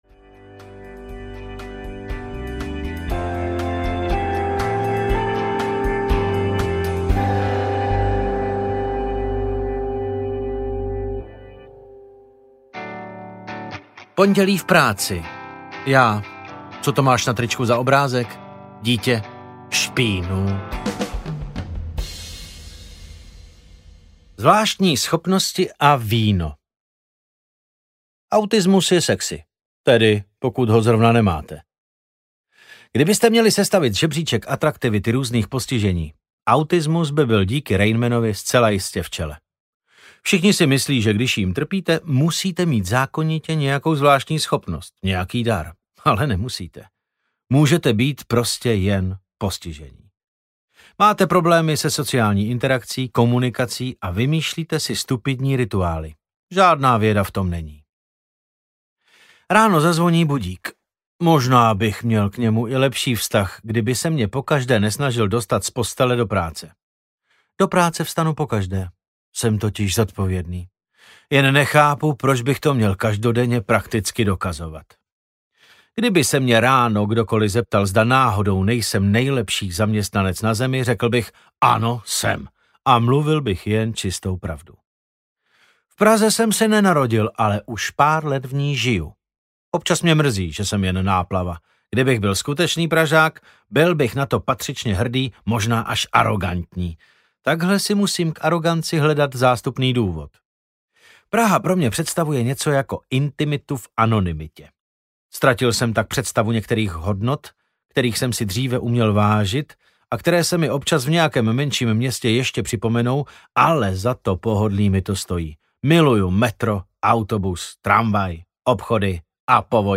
Autismus & Chardonnay (1+2) audiokniha
Ukázka z knihy
• InterpretDavid Novotný